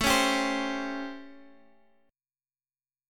A7#9 chord